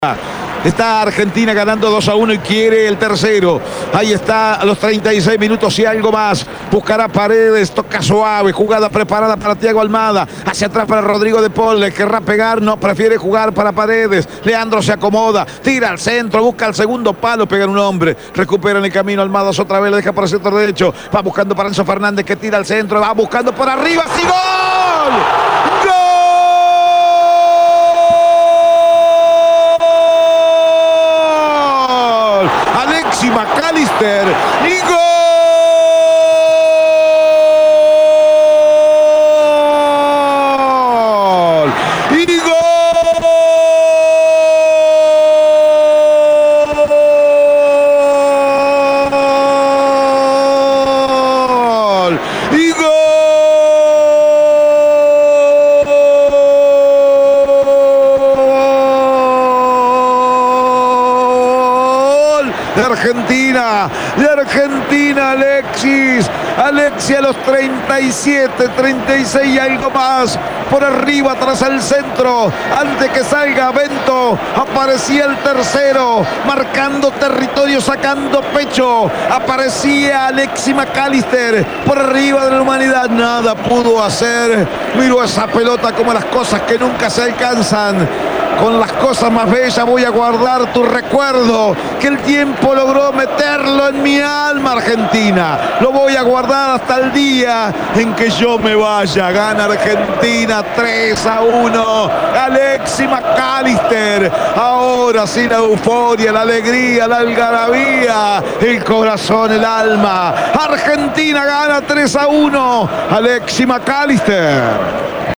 EN EL RELATO